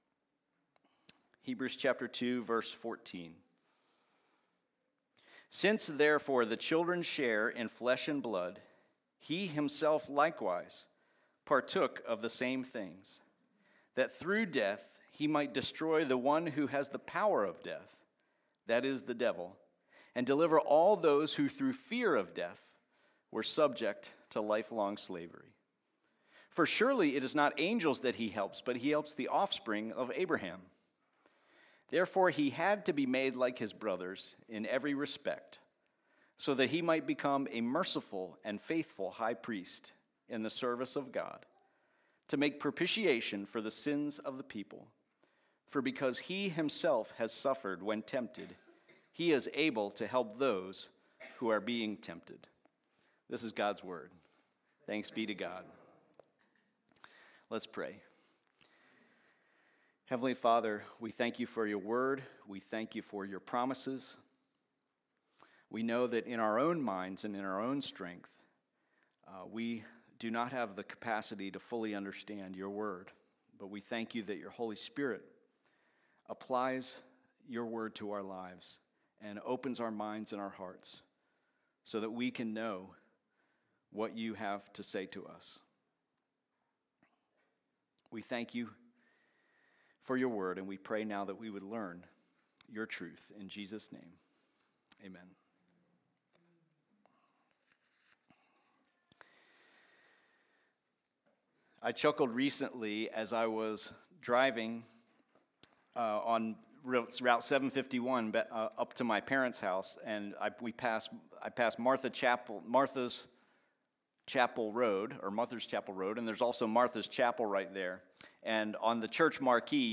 "Victory over Death" Easter Message - Trinity Community Church